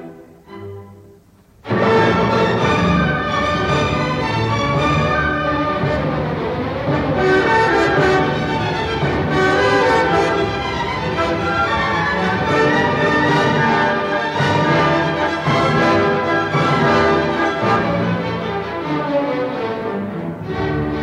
Brahms Symphony 2 orchestral violin excerpt 4th movement
Bruno Walter: Vienna Philharmonic Orchestra, 1936 Half note =112